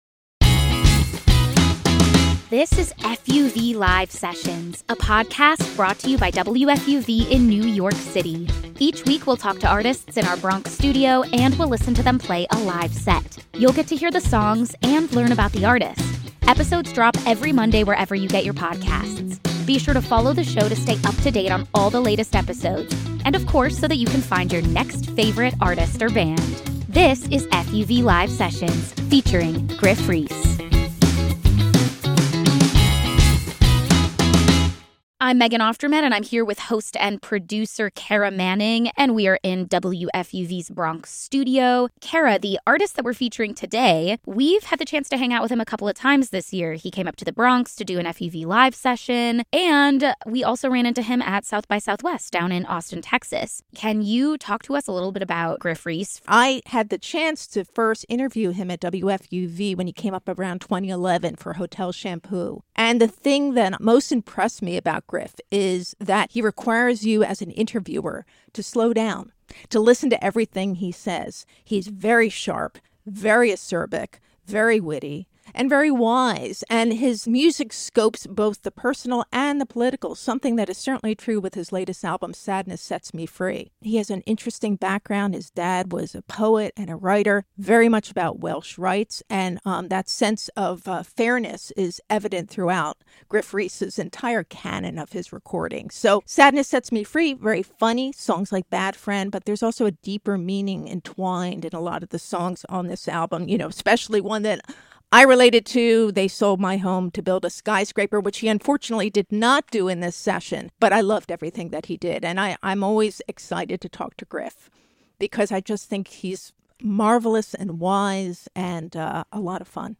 for a live set